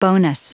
Prononciation du mot bonus en anglais (fichier audio)
Prononciation du mot : bonus